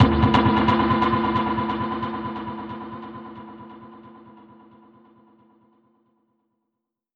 Index of /musicradar/dub-percussion-samples/134bpm
DPFX_PercHit_C_134-11.wav